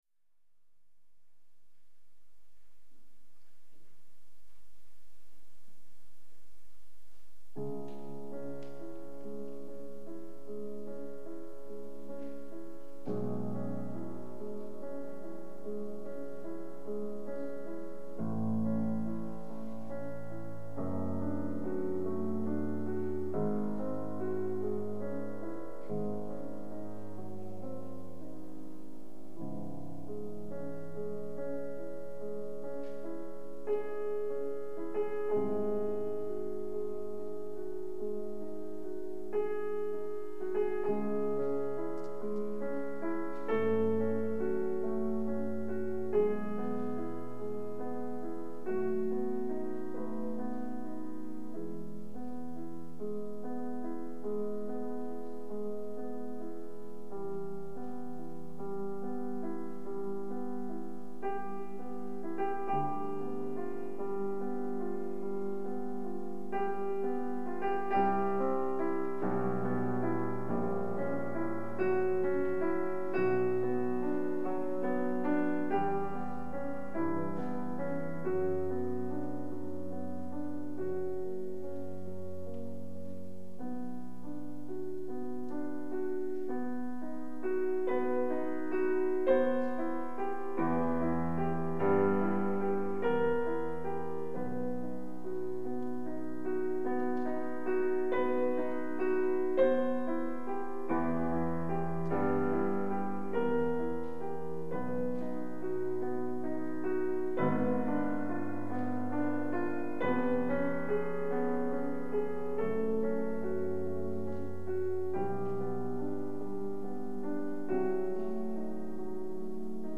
piano) Piano: Bösendorfer Mödlinger Bühne, Mödling, 9 June 1988.
Sonata for piano in c sharp minor
Adagio sostenuto Allegretto Presto agitato